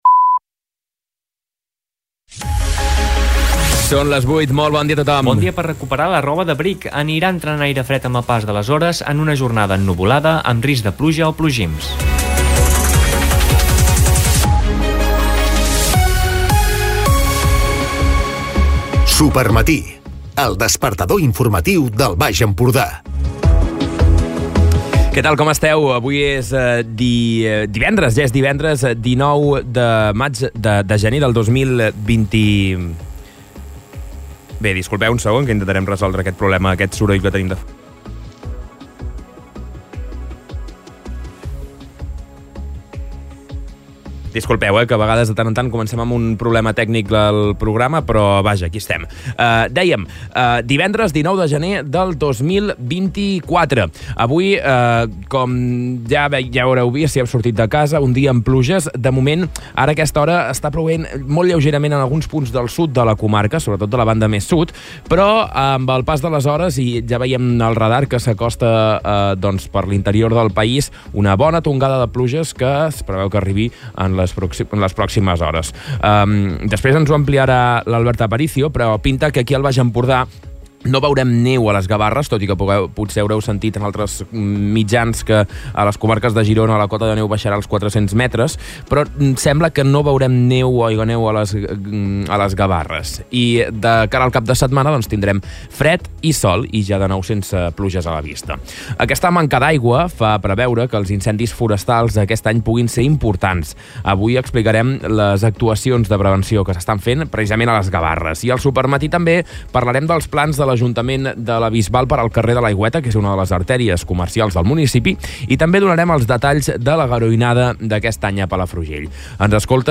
Escolta l'informatiu d'aquest divendres